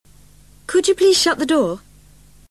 PITCH IN BRITISH ENGLISH
After listening to each audio file, repeat it aloud trying to imitate the intonation:
COLLEAGUE TO COLLEAGUE